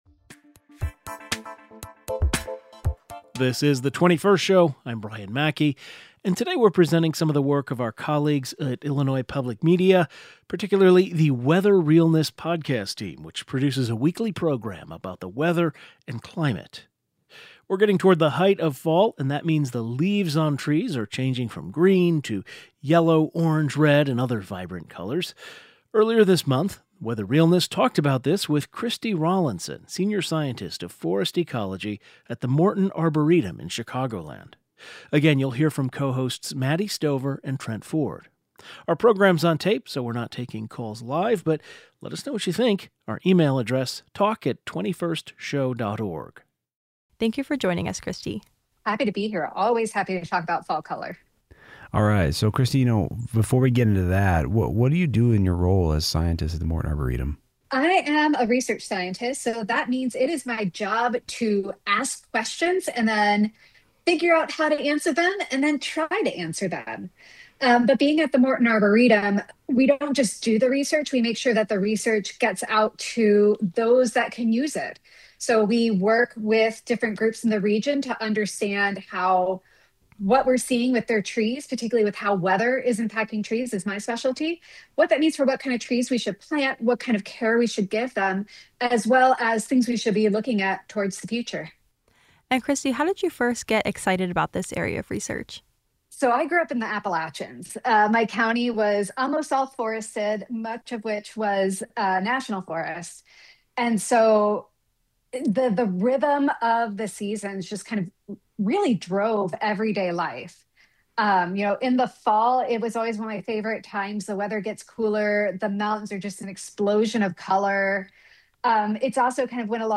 Today we’re talking about the weather, with a pair of conversations from “Weather Realness,” a sister podcast produced by Illinois Public Media.